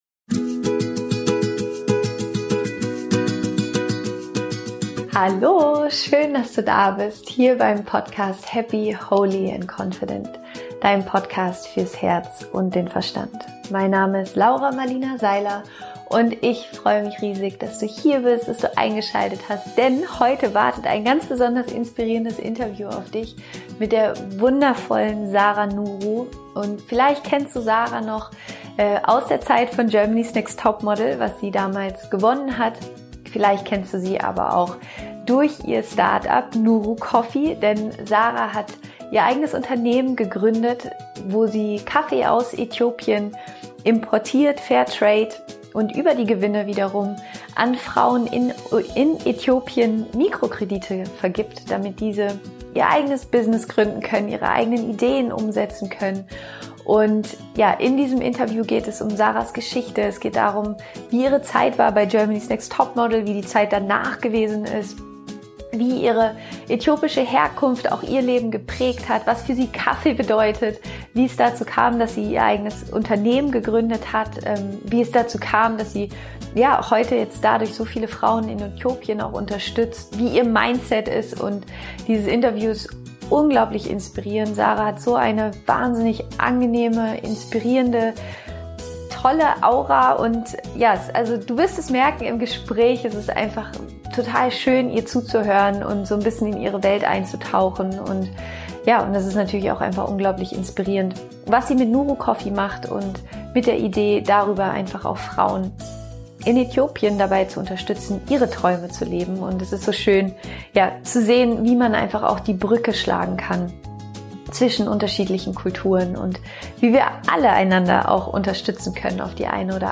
Vor ein paar Tagen habe ich mit der wundervollen Sara Nuru an unserem Wohnzimmertisch Kuchen gegessen und mit ihr ein super inspirierendes Interview über ihren Weg aufgenommen.